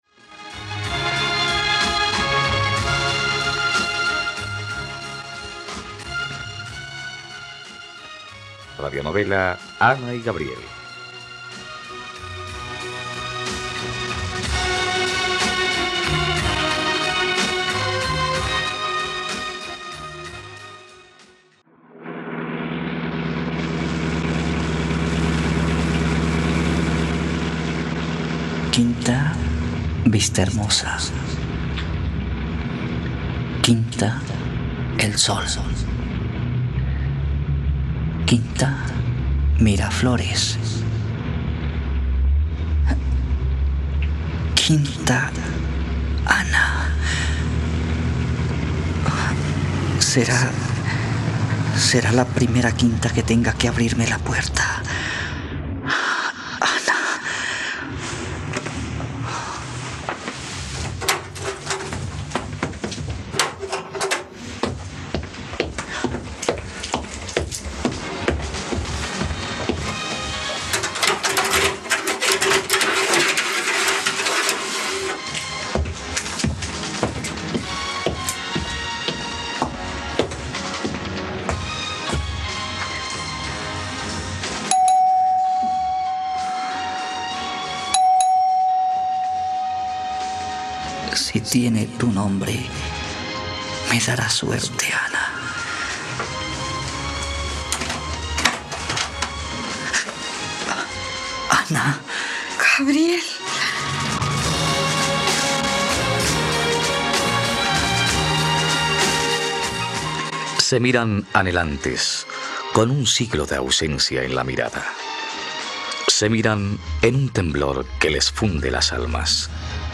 Ana y Gabriel - Radionovela, capítulo 78 | RTVCPlay